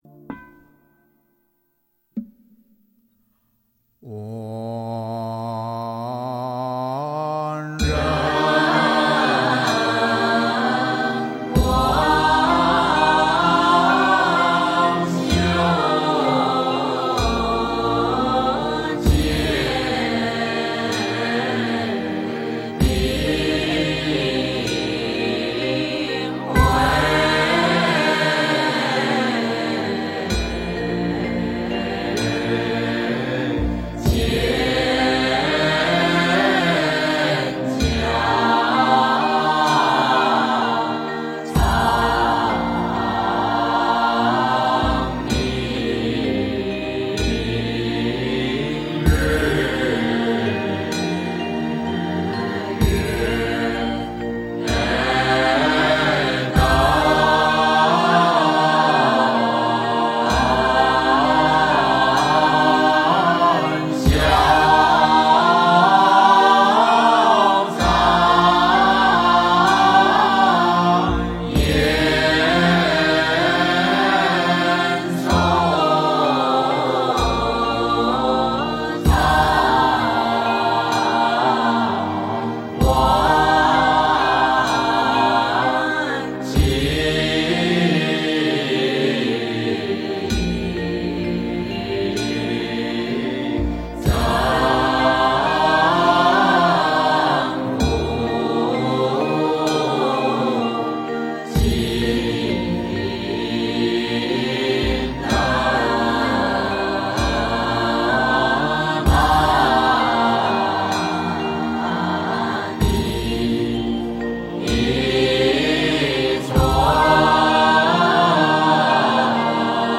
药师偈 诵经 药师偈--如是我闻 点我： 标签: 佛音 诵经 佛教音乐 返回列表 上一篇： 杨枝净水赞 下一篇： 赞佛偈 相关文章 般若心咒 般若心咒--琼英卓玛...